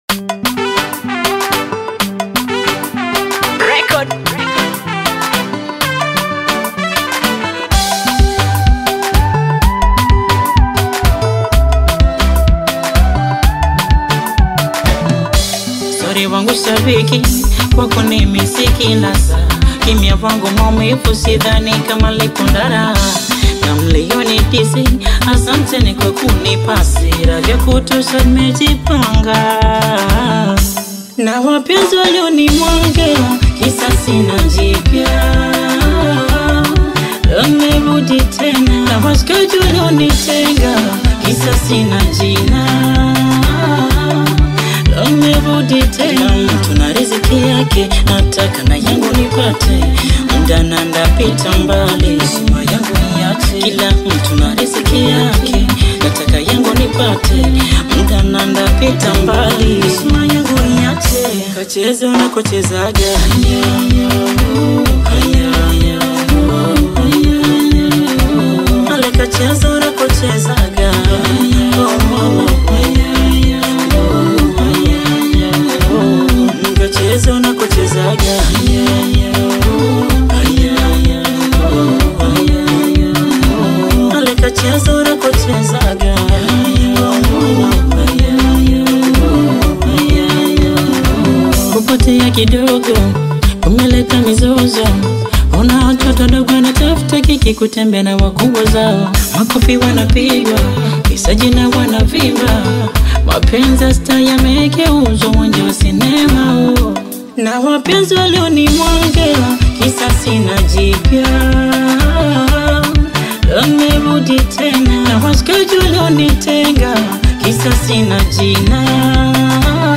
AudioBongo fleva
Bongo Flava/Afro-Pop single